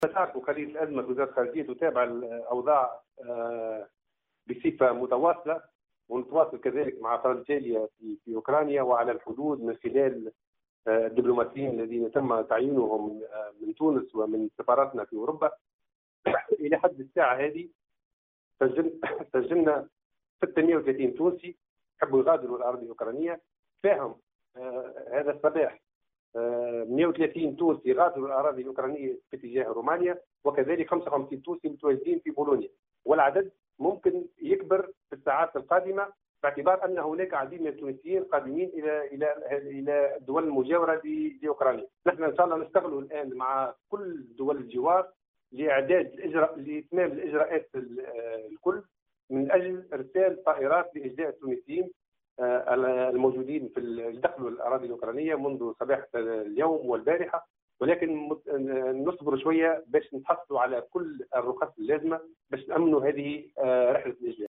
وقال في تصريح اليوم لـ"الجوهرة أف ام" أن هذا العدد مُرجّح للارتفاع خلال الساعات القادمة، مشيرا أيضا إلى أن التنسيق متواصل مع الدول التي استقبلتهم لاستكمال الإجراءات اللازمة وإرسال طائرات لجلبهم.